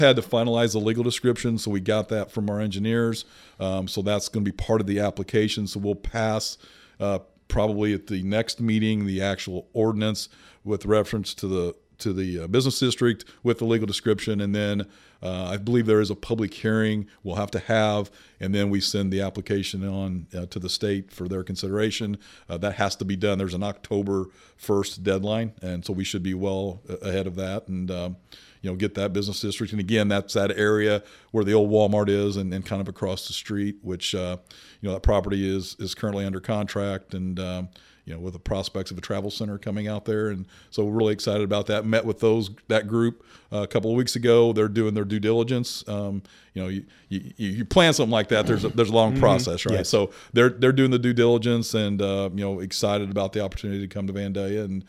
Speaking during our weekly podcast “Talking about Vandalia,” Mayor Doug Knebel says the Business District for a very specific part of town is now ready to move forward.